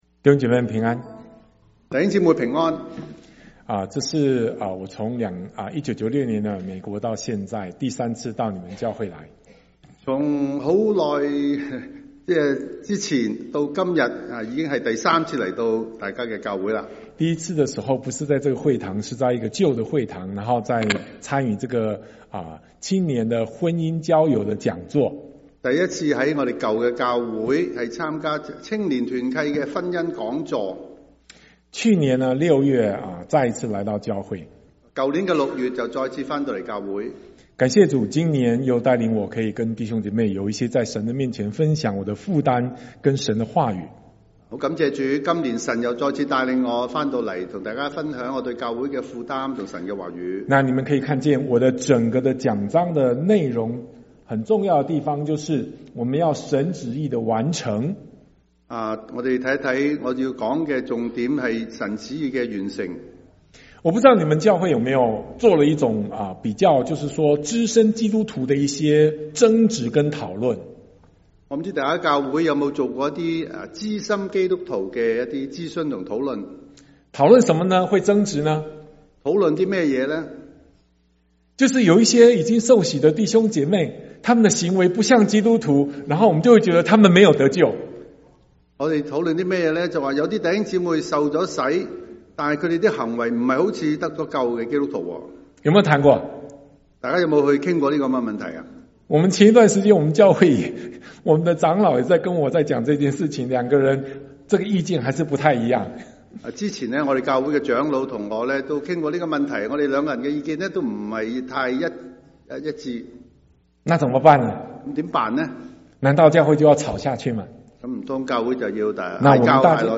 1/19/2025 國粵語聯合崇拜: 「得救生命的方向」